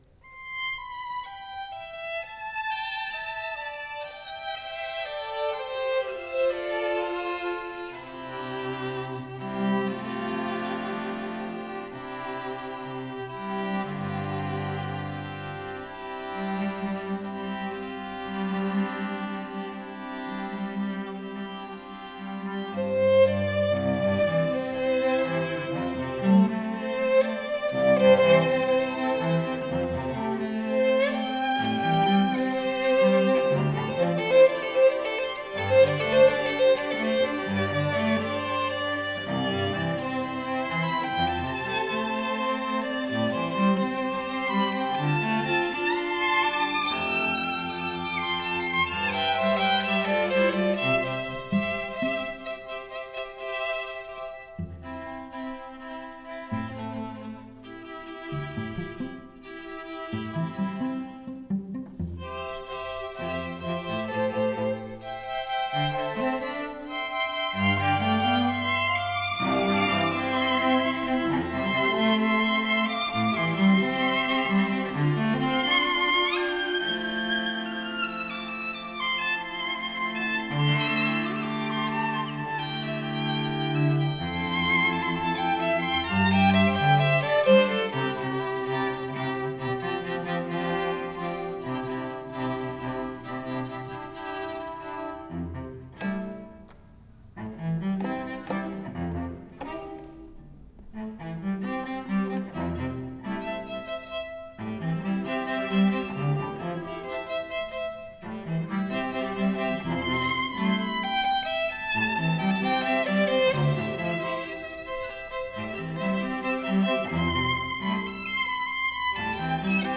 for String Quartet (2022)
Descending scales (blessings) open the music.